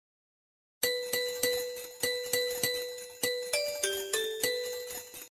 Категория: SMS рингтоны